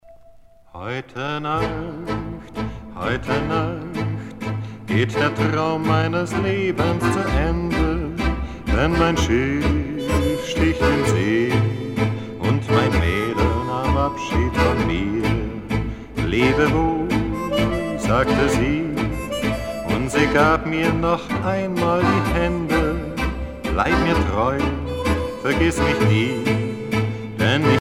danse : tango musette
Pièce musicale éditée